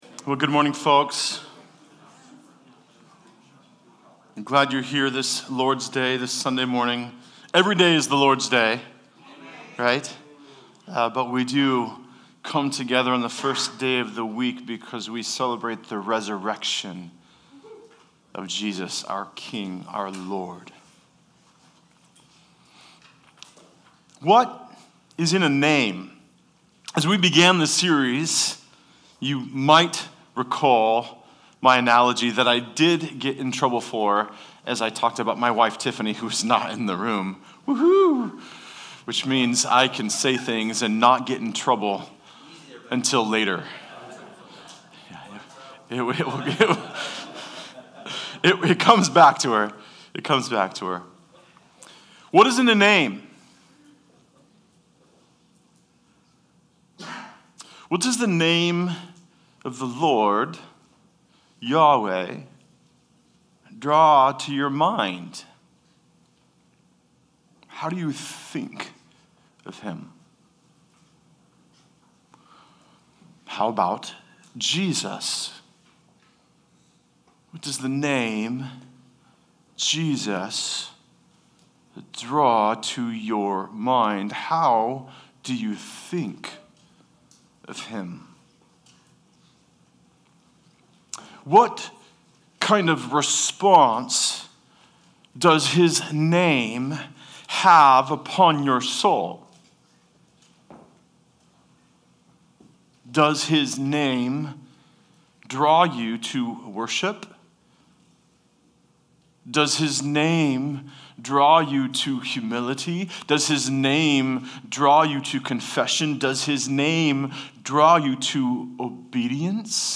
Passage: Exodus 5-6:27 Service Type: Sunday Service